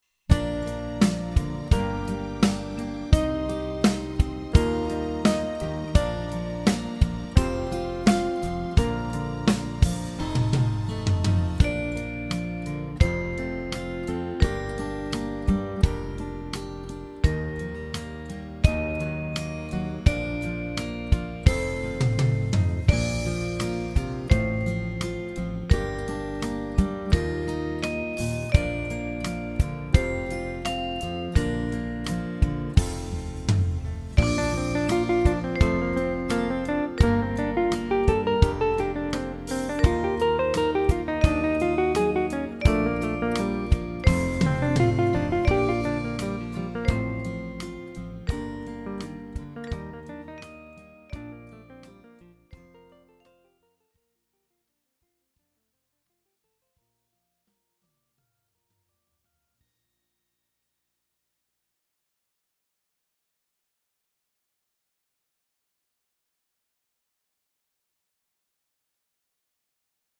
Maza dziesmiņa Play-along.